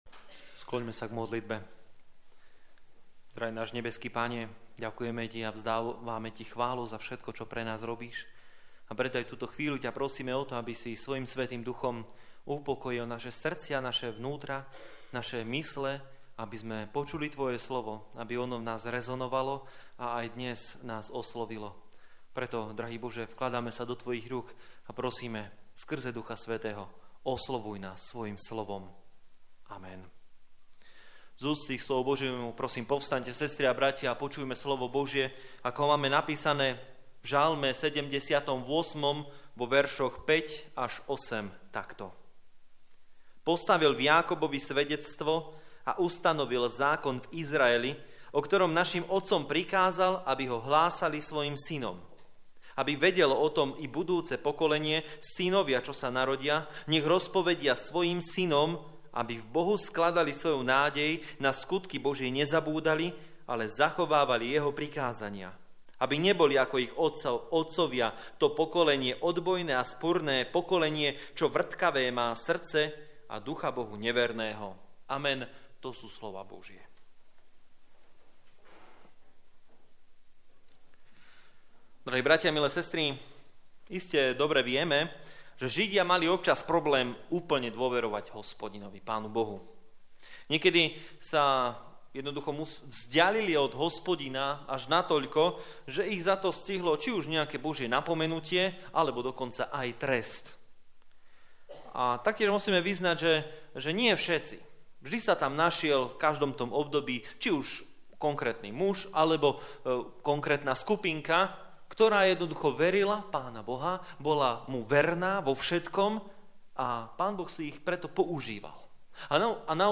8 Service Type: Služby Božie 19. nedeľa po Svätej Trojici « Modlitba a žehnanie Vďačnosť za dary…